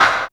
CLAP DM2.1.wav